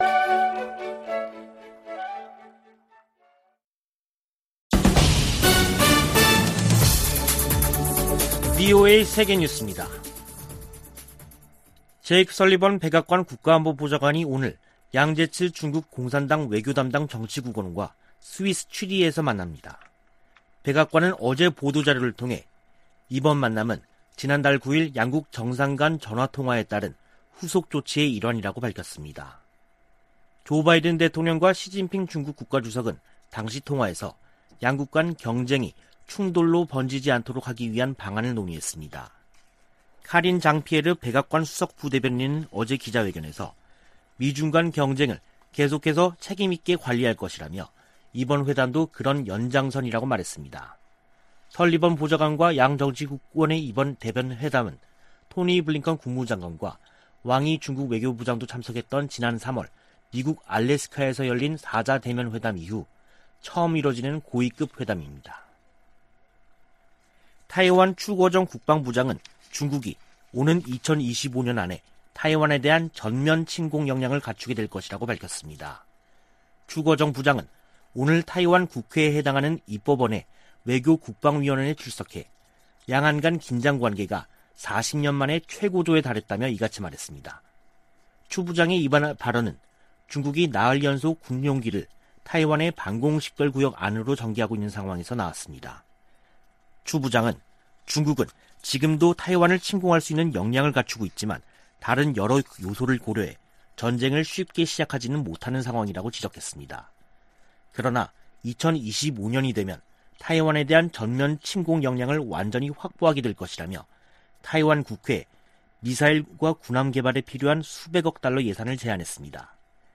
VOA 한국어 간판 뉴스 프로그램 '뉴스 투데이', 2021년 10월 6일 3부 방송입니다. 미 국무부는 유엔 안전보장이사회 전문가패널 중간보고서 발표와 관련, 북한의 계속되는 불법 활동에 우려를 나타냈습니다. 유럽연합과 덴마크 등이 유엔에서, 북한의 핵과 탄도미사일 프로그램이 역내 안보 위협이라고 지적했습니다.